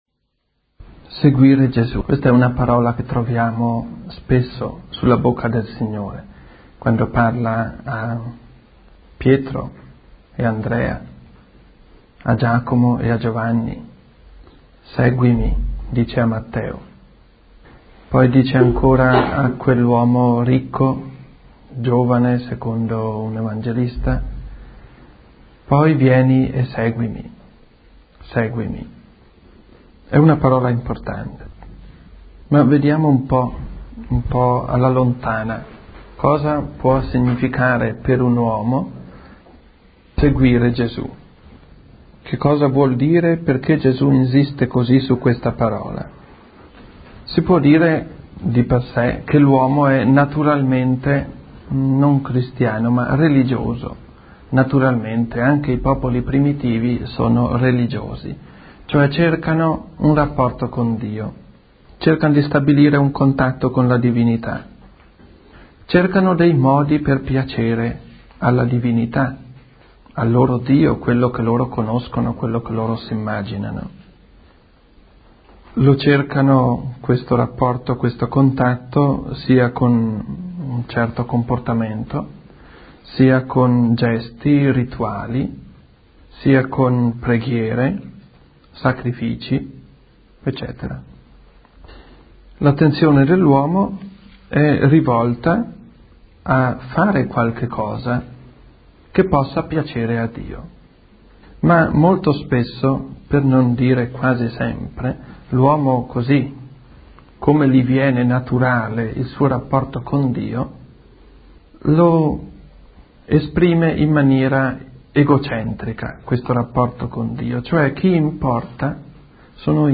Conversazioni varie La morte (39:05) Seguire Gesù (31:13) Accoglienza